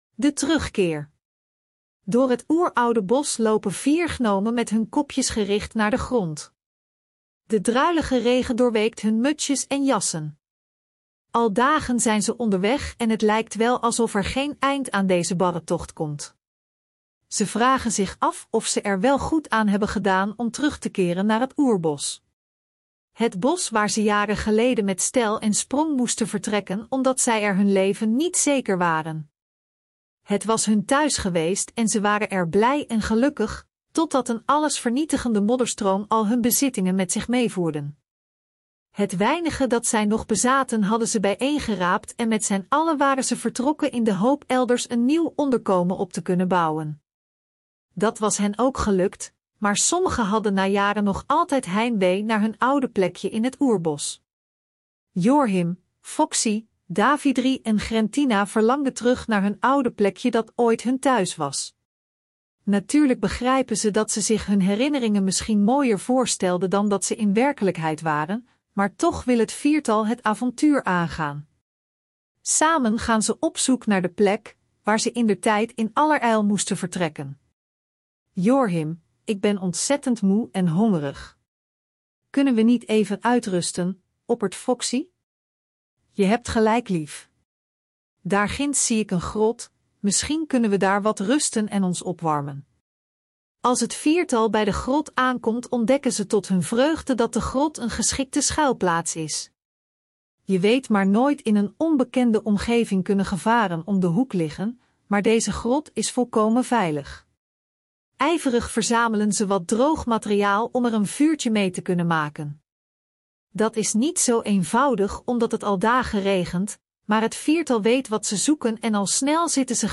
Ook als lusterverhaal te beluisteren